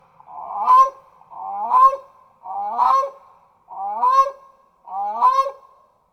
Grey Crowned Crane
Guard Call | A sharp, single call expressing alarm.
Grey-Crowned-Crane-Alarm-Call.mp3